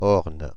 The Orne (French: [ɔʁn]
Fr-Paris--Orne.ogg.mp3